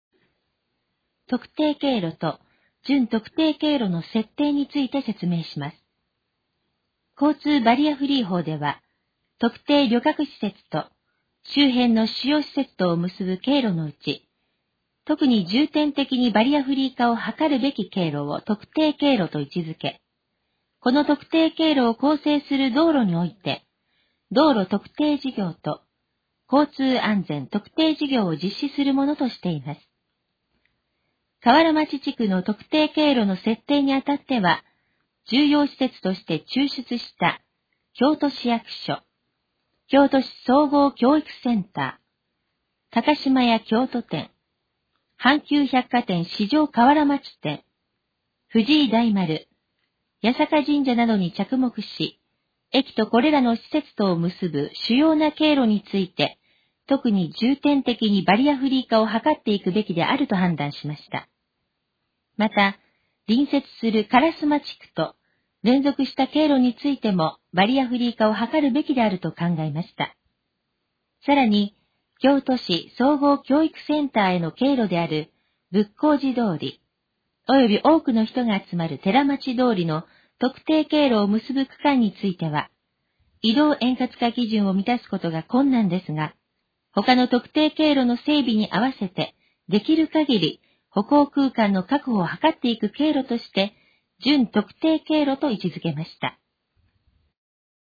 以下の項目の要約を音声で読み上げます。
ナレーション再生 約191KB